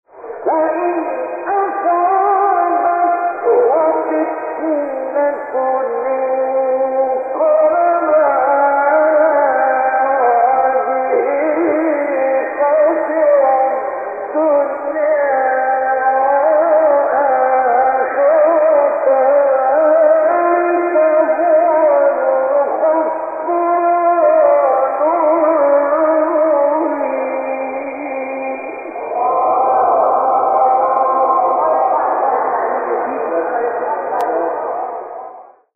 آیه 11 سوره حج استاد مصطفی اسماعیل | نغمات قرآن | دانلود تلاوت قرآن